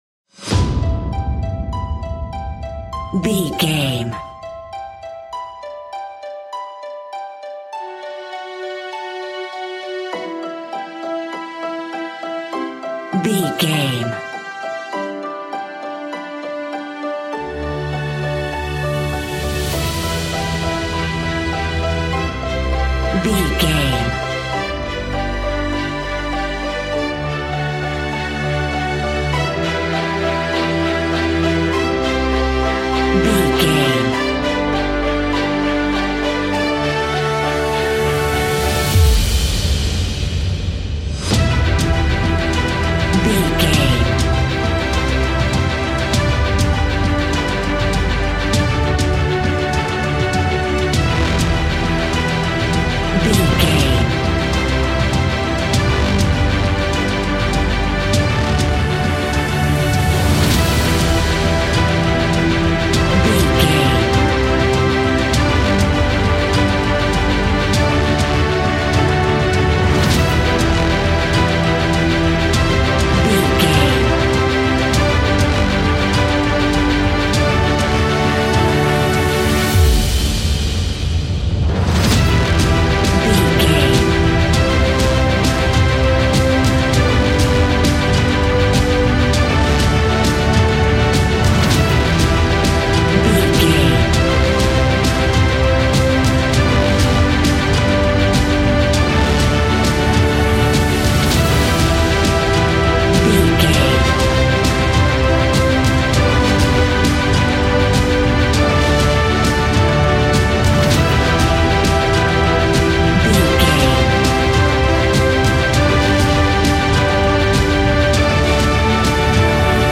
Epic / Action
In-crescendo
Uplifting
Ionian/Major
orchestra
dramatic
energetic